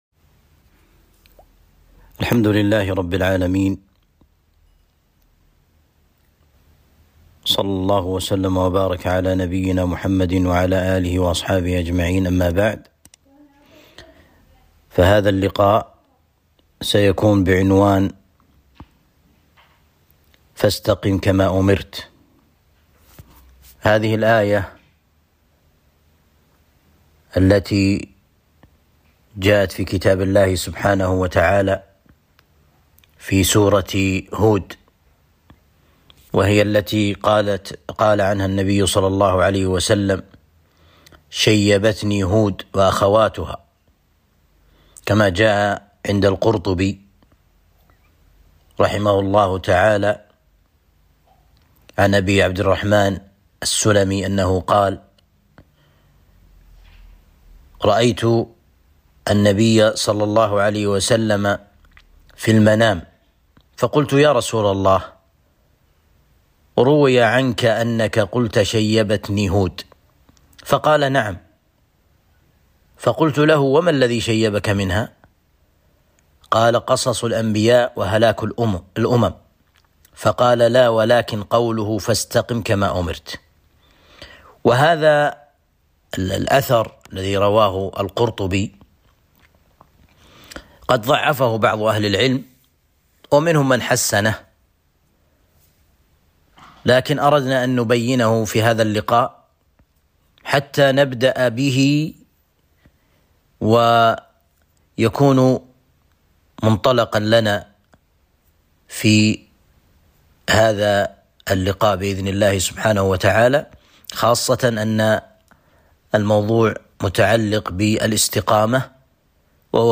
محاضرة